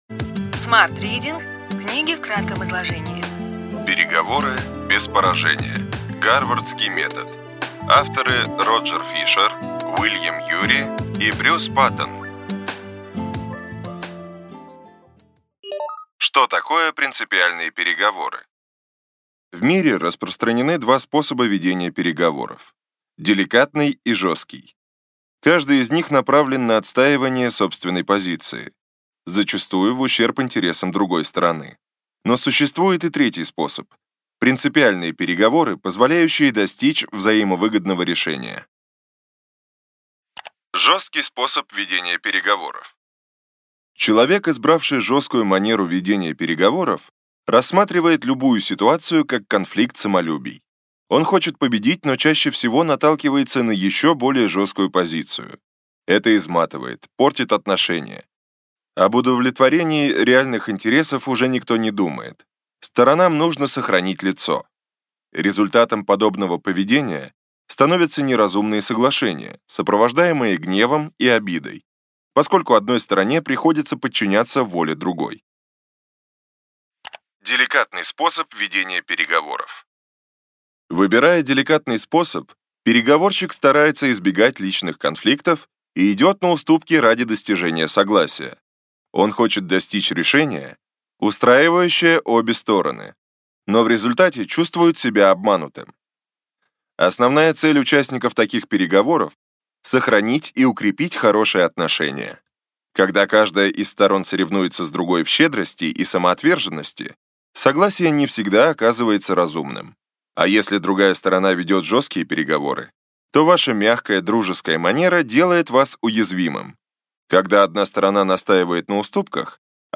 Аудиокнига Ключевые идеи книги: Переговоры без поражения. Гарвардский метод.